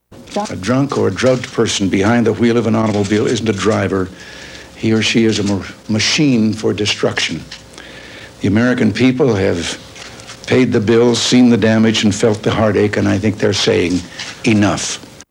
U.S. President Ronald Reagan makes a statement about drugged and drunken driving